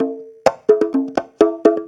Conga Loop 128 BPM (14).wav